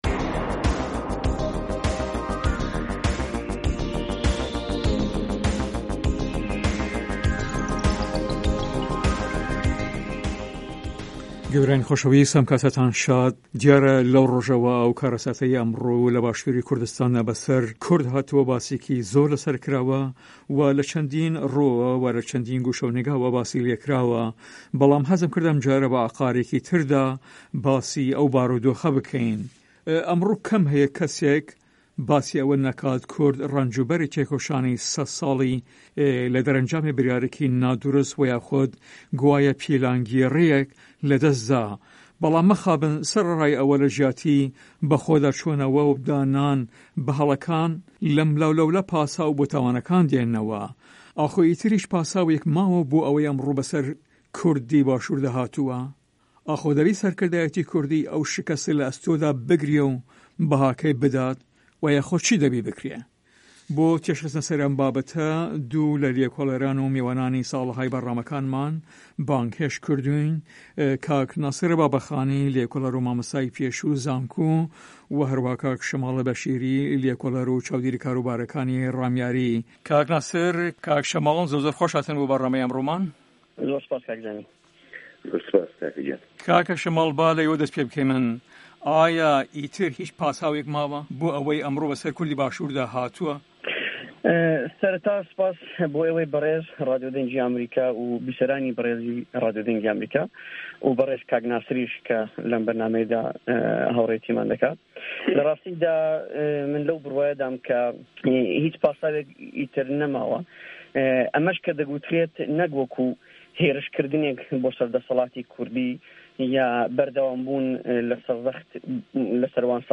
مێزگرد: شکه‌ست، کاره‌سات، پیلانگێڕی،سه‌ران، تاوان، به‌رپرسیاره‌تی، دادگاییکردن، ده‌ست له‌ کارکێشانه‌وه‌